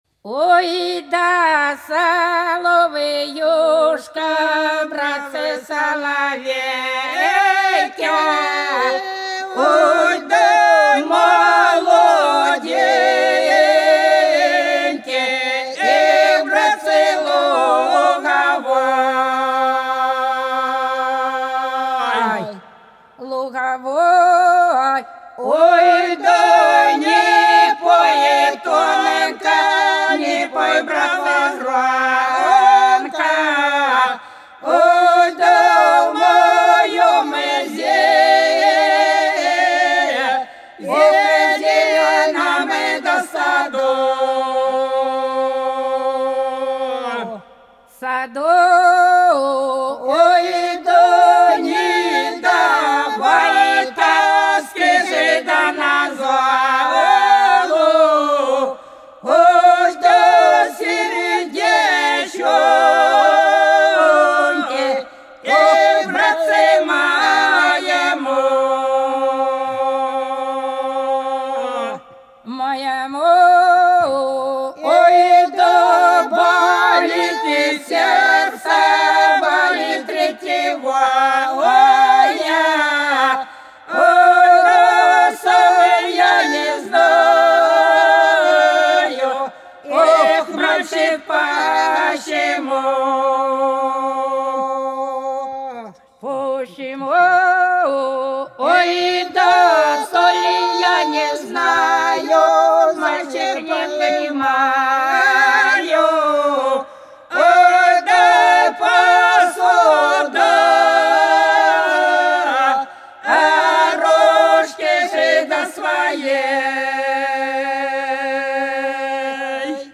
По-над садом, садом дорожка лежала Ой, да соловеюшка, братцы, соловей - протяжная (с.Плёхово, Курской области)
08_Ой,_да_соловеюшка,_братцы,_соловей_(протяжная).mp3